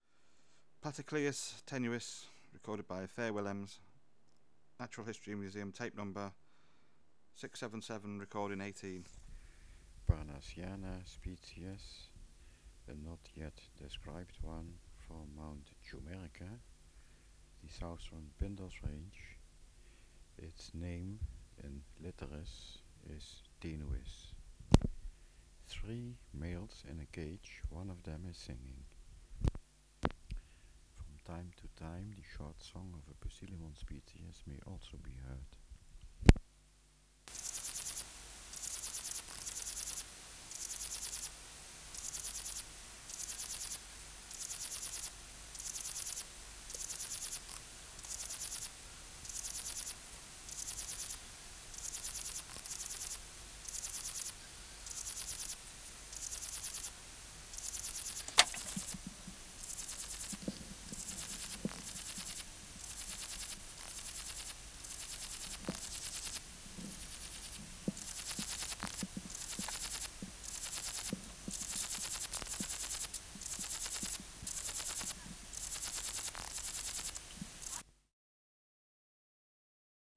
568:43 Platycleis tenuis (677r18) | BioAcoustica
Extraneous Noise: Poecilimon Substrate/Cage: In cage Biotic Factors / Experimental Conditions: 2 non-singing males also in cage
Microphone & Power Supply: AKG D202E (LF circuit off) Distance from Subject (cm): 4